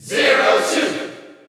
Crowd cheers (SSBU) You cannot overwrite this file.
Zero_Suit_Samus_Cheer_Dutch_SSBU.ogg